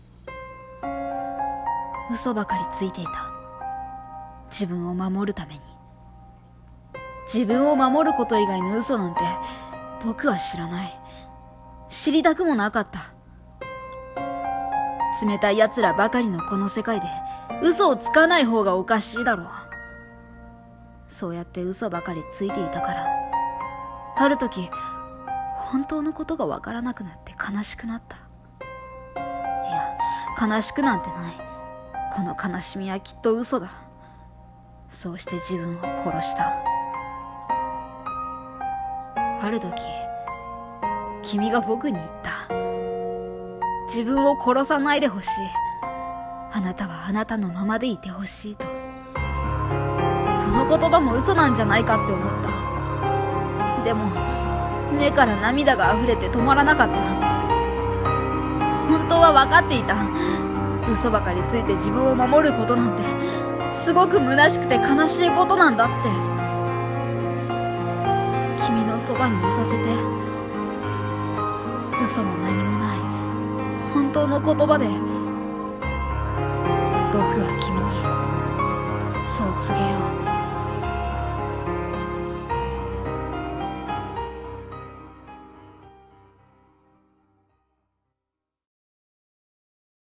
【朗読声劇】